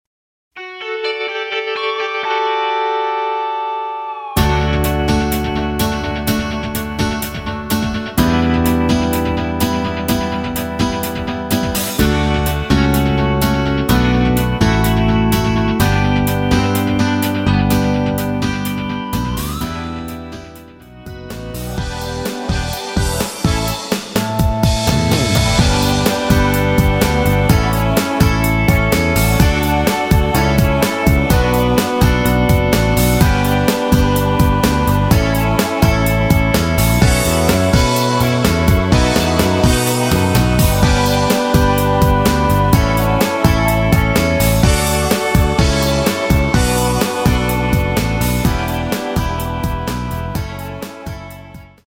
음정은 반음정씩 변하게 되며 노래방도 마찬가지로 반음정씩 변하게 됩니다.
앞부분30초, 뒷부분30초씩 편집해서 올려 드리고 있습니다.